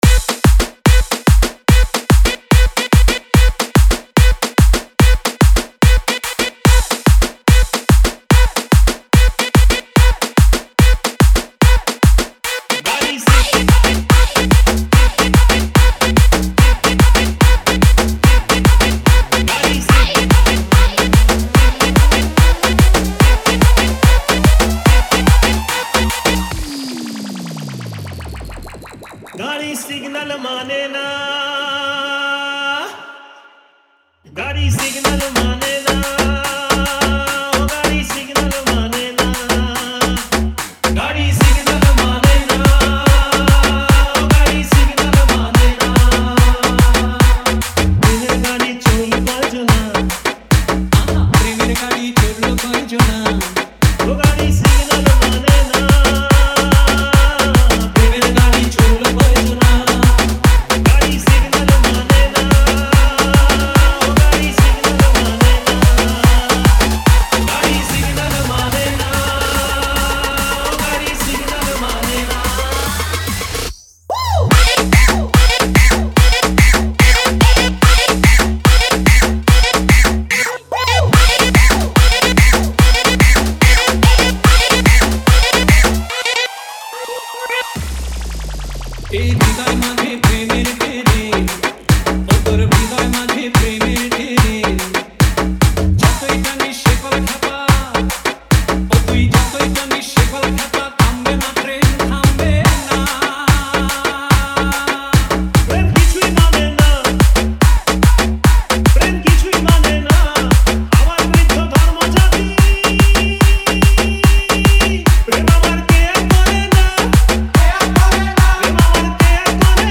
Tollywood Single Remixes